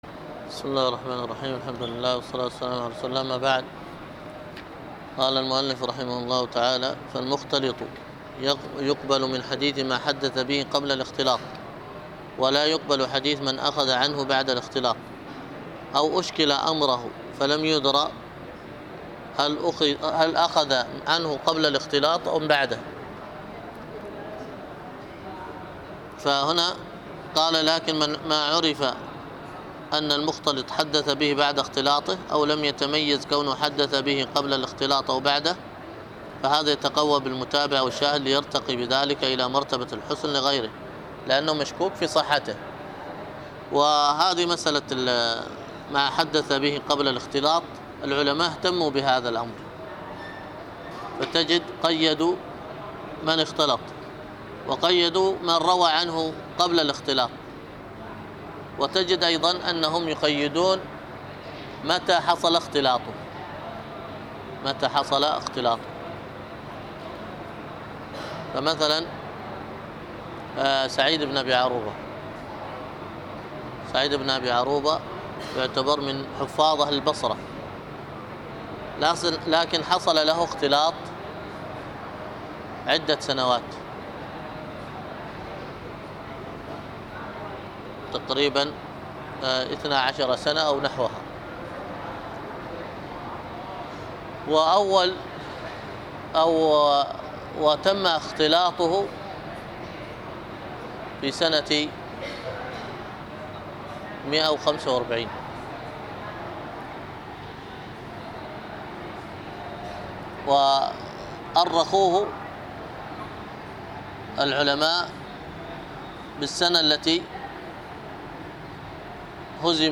الدرس في شرح كتاب ضوابط الجرح والتعديل 58